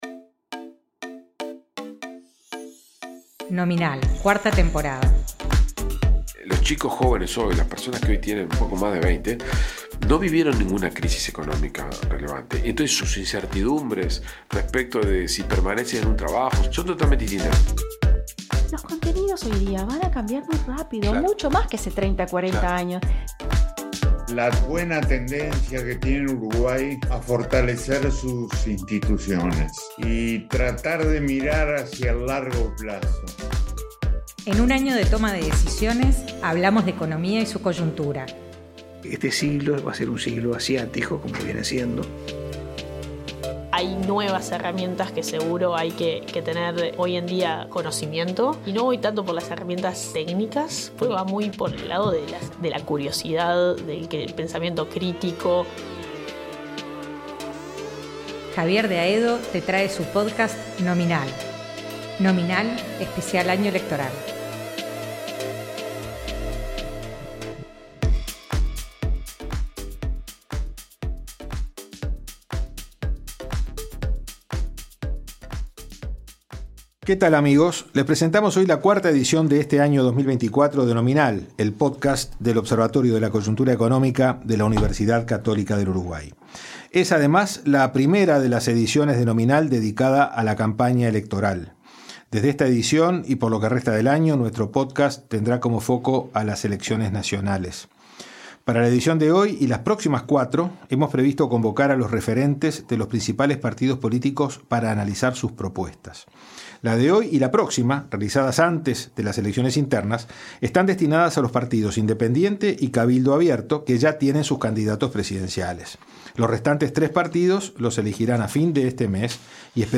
La carrera electoral tendrá su primer mojón el 30 de junio, con las elecciones internas. En este año de decisiones, Nominal comienza un ciclo de entrevistas a asesores de los candidatos.